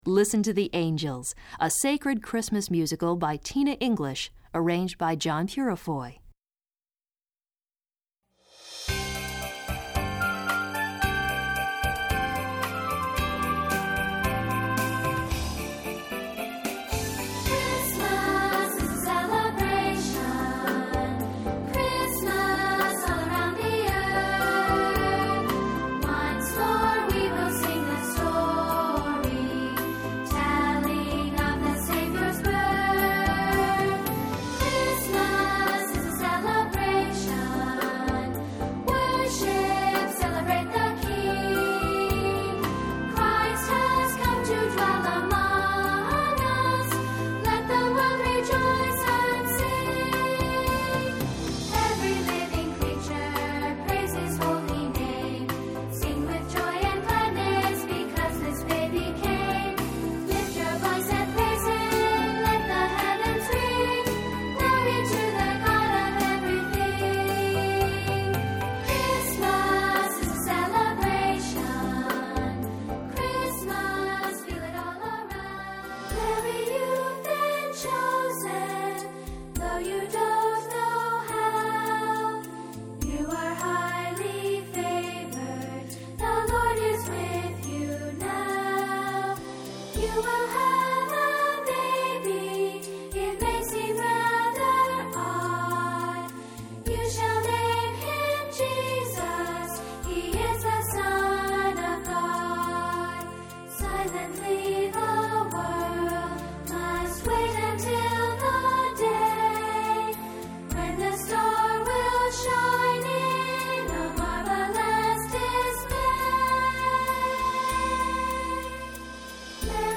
Voicing: Accompaniment CD